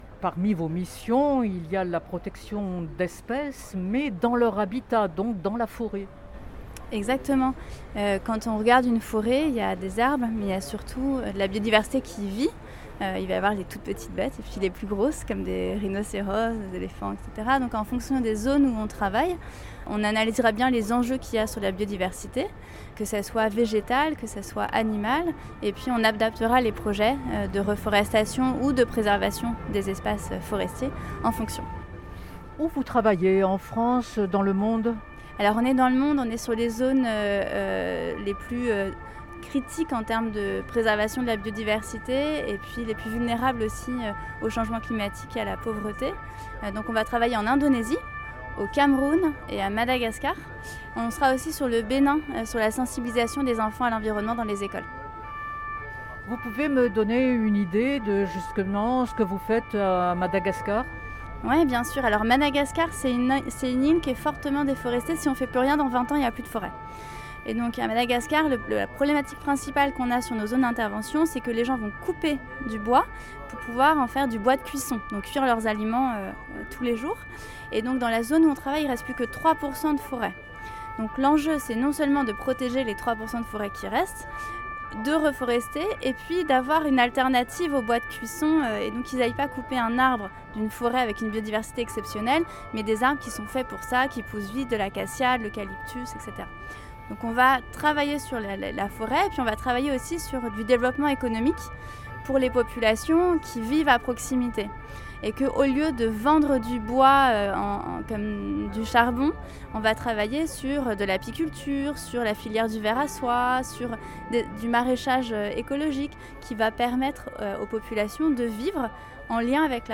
(4/4) Retour sur… Congrès mondial de la nature. Entretien